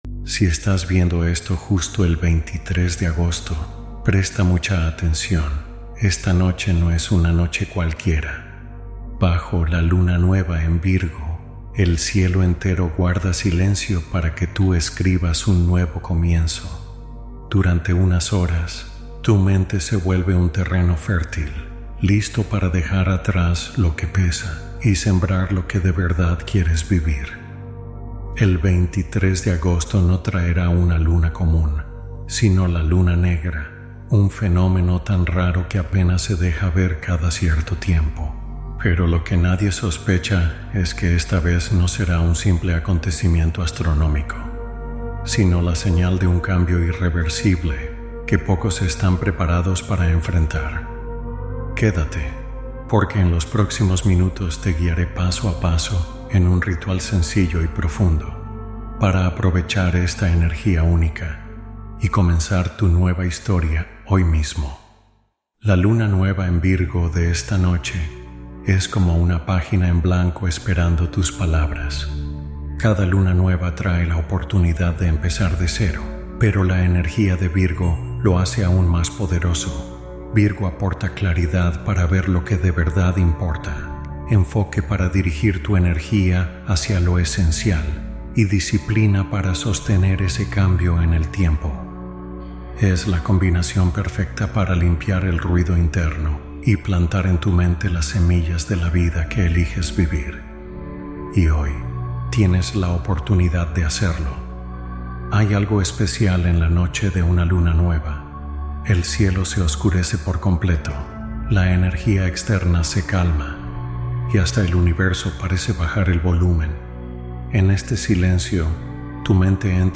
Meditación de la Luna Negra: El Despertar que Llega Esta Noche